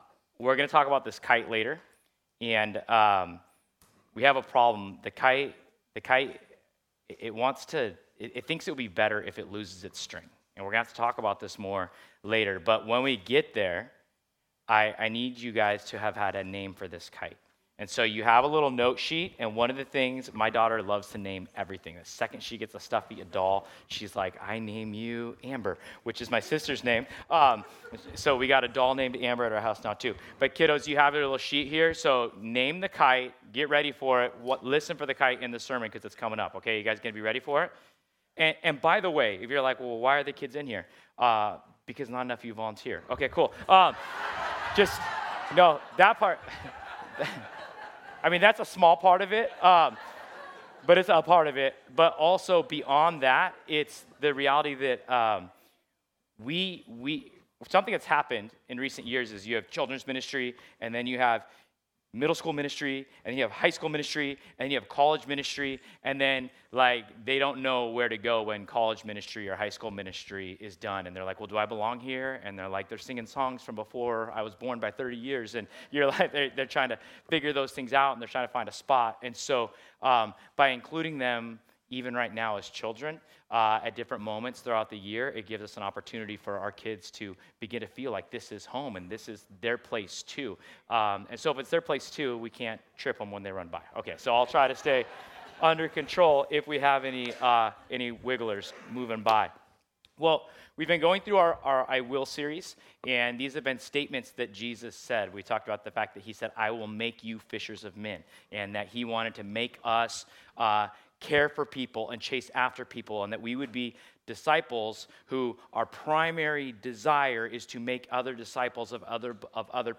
Matthew 11:27-30 Service Type: Sunday This Sunday